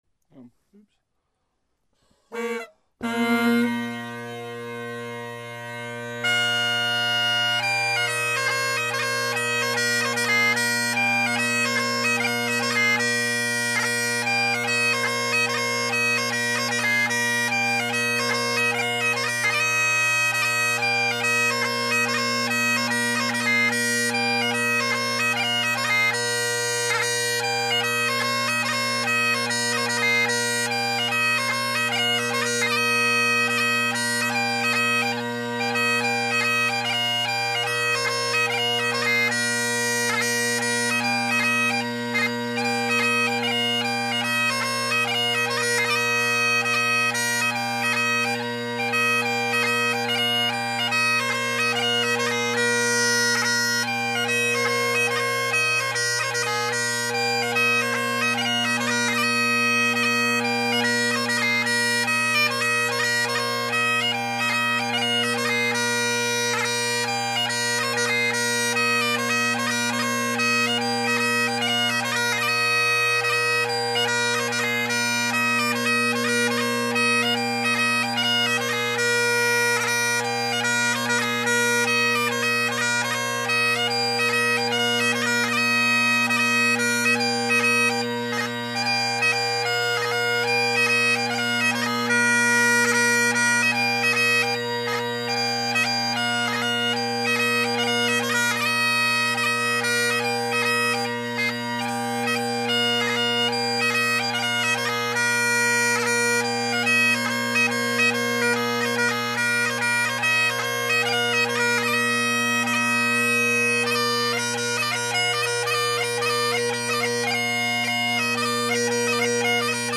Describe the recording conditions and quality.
It has a Husk reed in it and I’ve got the Crozier glass tongued reeds in my Gellaitry’s. I’ve got the only competition I go to coming up in mid-November so I’m practicing for that.